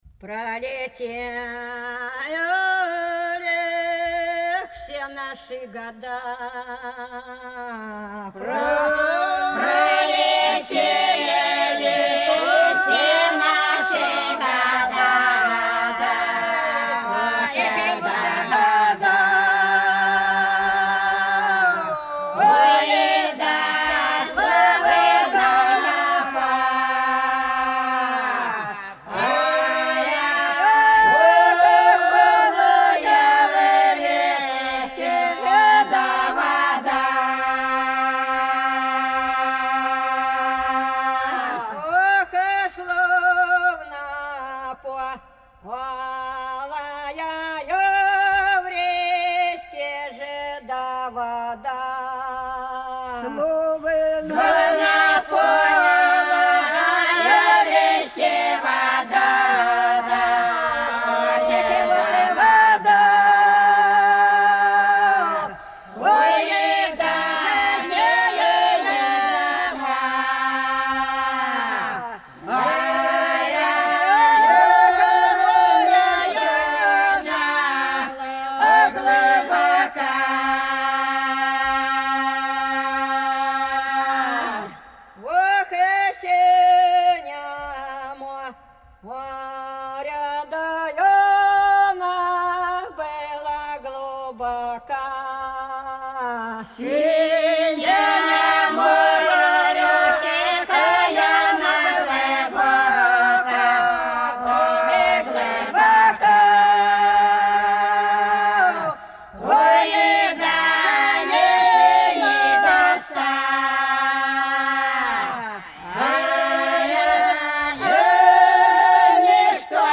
Пролетели все наши года Пролетели все наши года – протяжная (Фольклорный ансамбль села Пчелиновка Воронежской области)
20_Пролетели_все_наши_года_–_протяжная.mp3